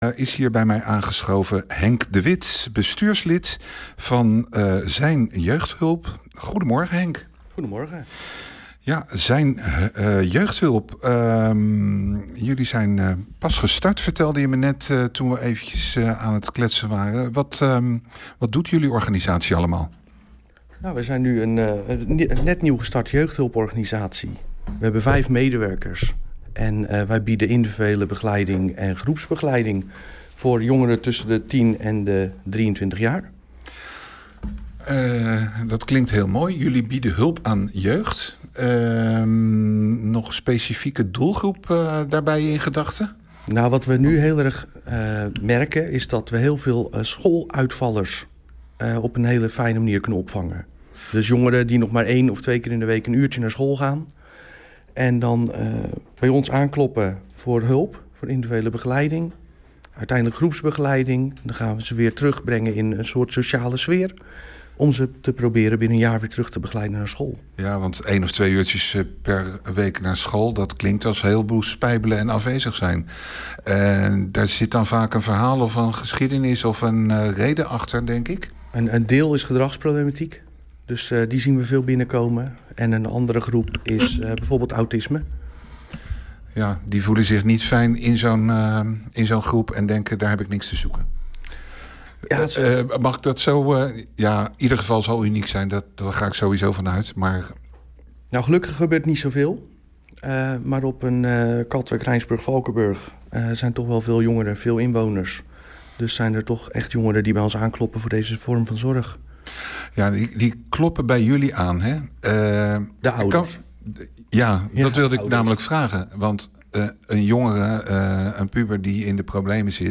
Klik op de foto voor het radio-interview